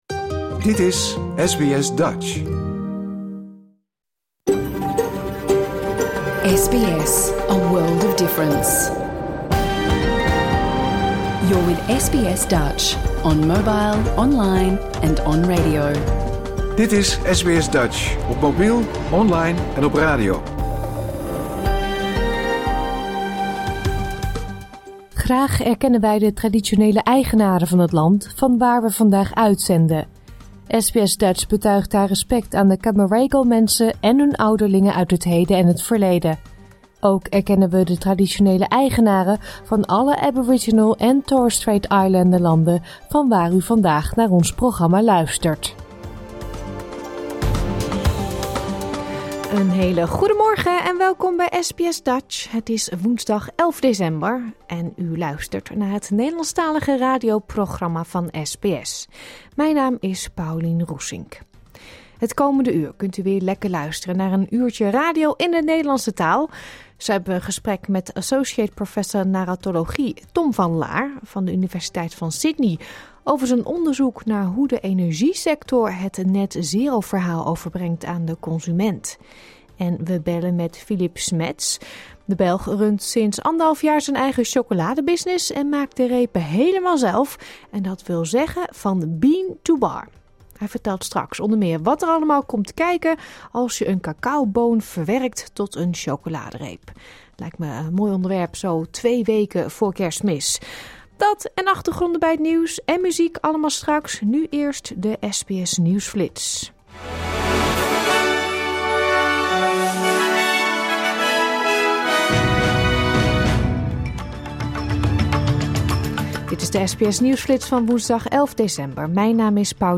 SBS Dutch gemist? Luister hier de uitzending van woensdag 11 december 2024 (bijna) integraal terug.